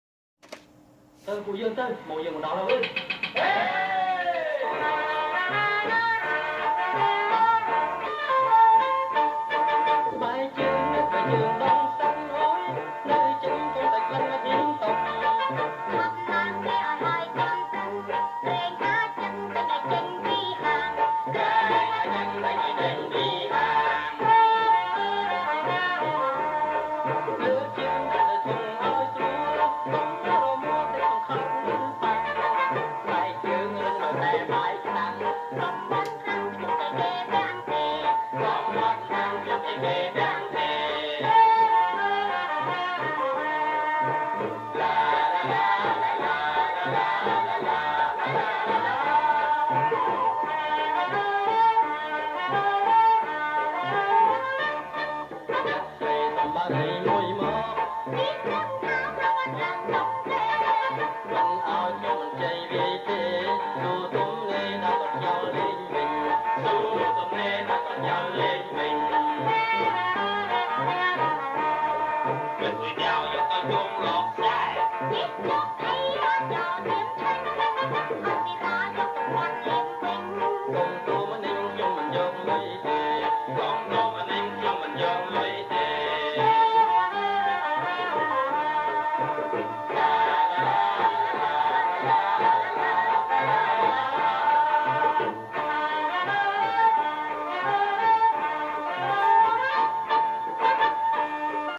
• ប្រគំជាចង្វាក់ តាលុង